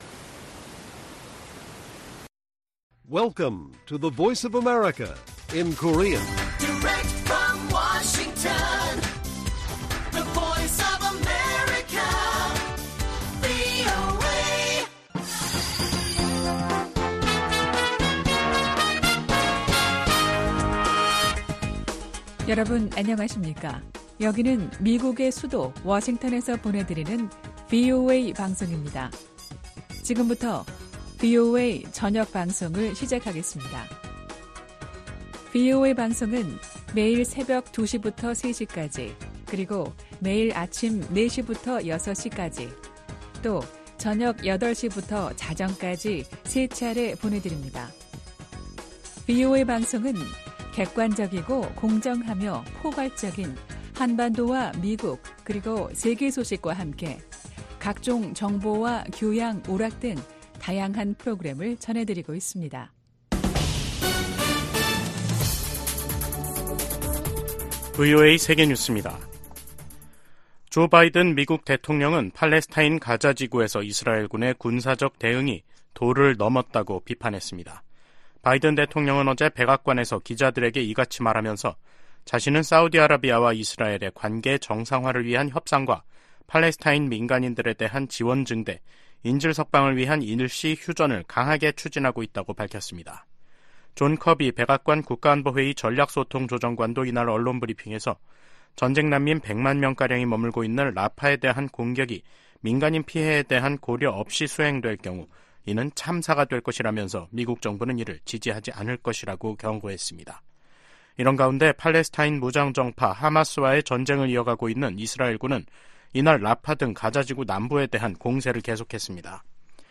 VOA 한국어 간판 뉴스 프로그램 '뉴스 투데이', 2024년 2월 9일 1부 방송입니다. 김정은 북한 국무위원장이 조선인민군 창건일인 8일 건군절 국방성 연설에서 한국을 제1 적대국가로 규정했다고 노동신문이 보도했습니다. 미 국무부는 북한 7차 핵실험 가능성을 경고한 주북 러시아 대사의 발언을 불안정하고 위태로우며 위험한 언행이라고 비판했습니다. 일부 전문가들이 한반도 전쟁 위기설을 제기한 가운데 미 국방부는 북한의 임박한 공격 징후는 없다고 밝혔습니다.